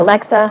synthetic-wakewords
ovos-tts-plugin-deepponies_Kim Kardashian_en.wav